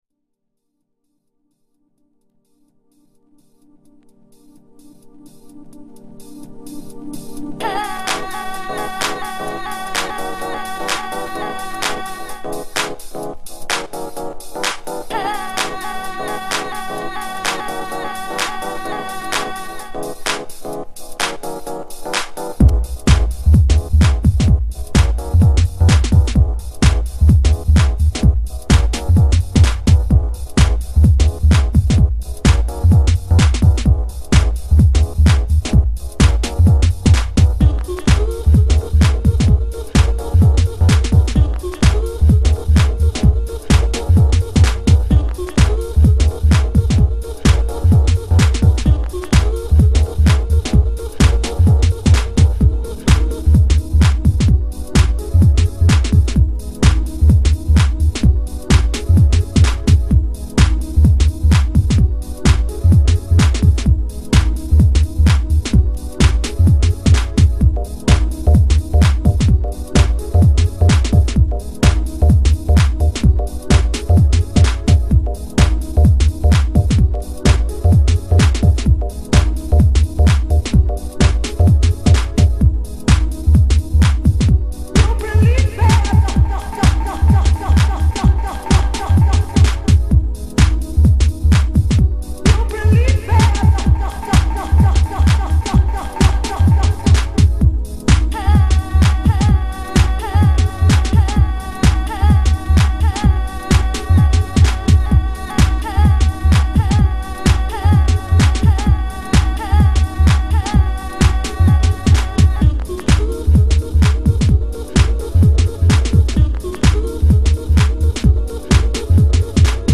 2009 at 3:53 pm Really enjoyed this mix.